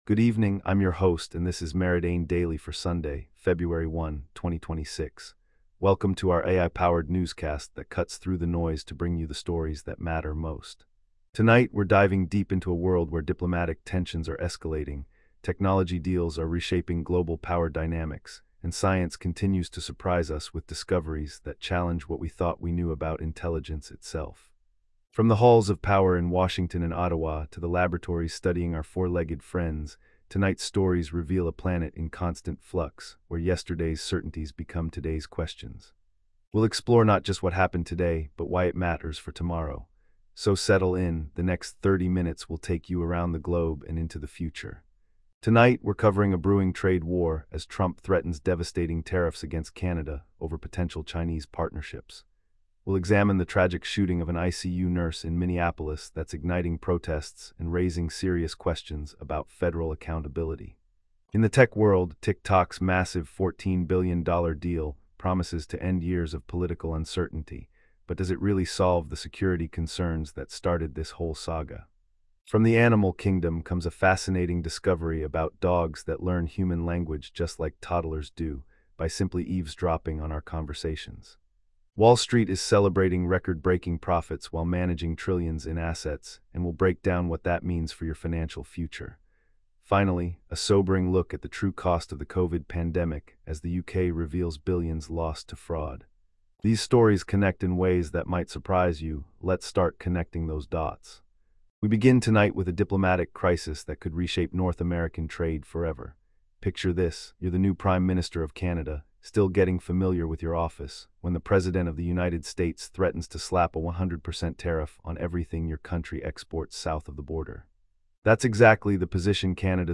Your nightly AI-powered news briefing for Feb 1, 2026